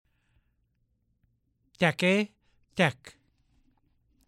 1. Vowel contrasts
E.  Listen to the difference between a and ä.